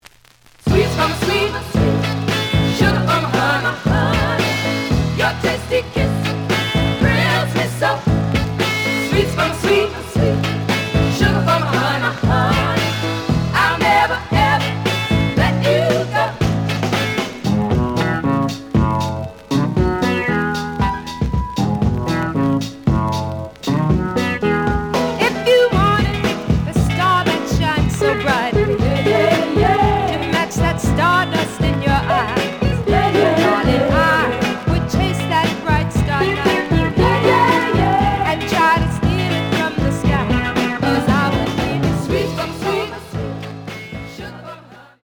The audio sample is recorded from the actual item.
●Genre: Soul, 60's Soul
A side plays good.